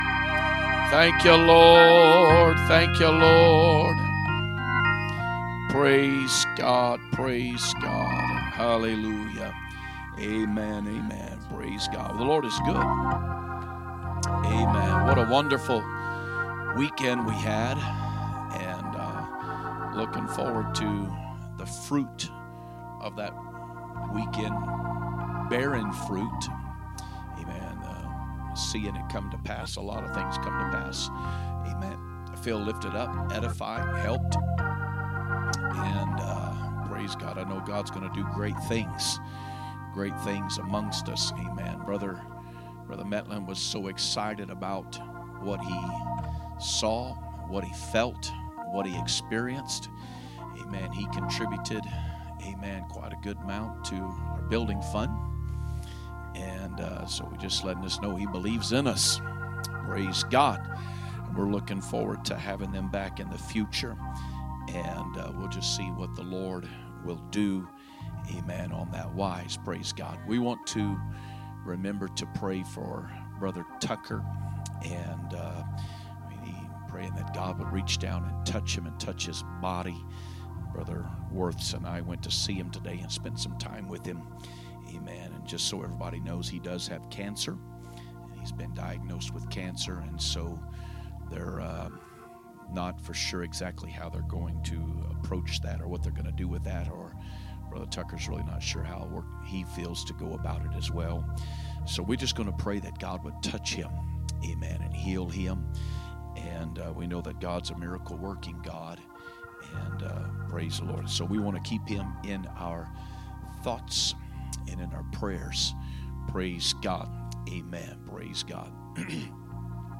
Wednesday Service
2025 Sermons